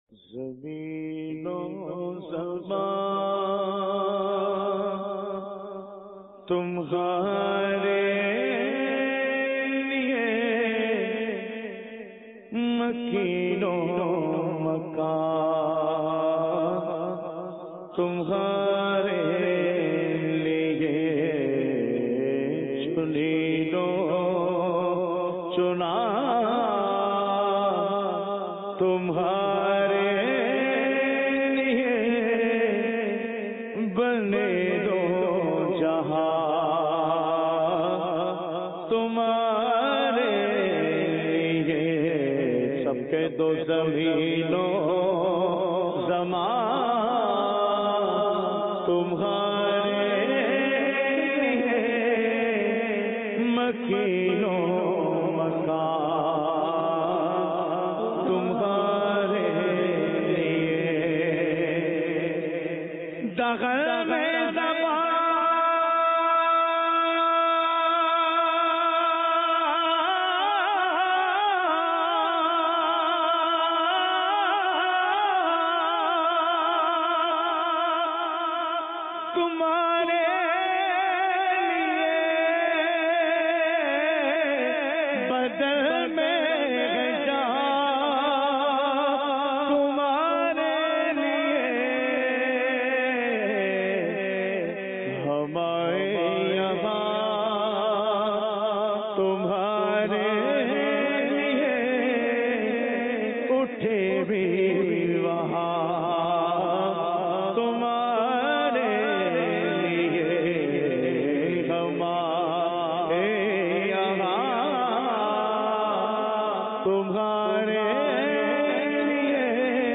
Zameen o Zama Tumhara Liye Feb 20, 2017 MP3 MP4 MP3 Share دعوتِ اسلامی کے تحت ہونےوالے اجتماعِ ذکرونعت میں پڑھا جانے والا سیدی اعلحٰضرت کا بہت ہی پیارا کلام خوبصورت آواز میں ضرور سنئے اور ایمان تازہ کیجئے۔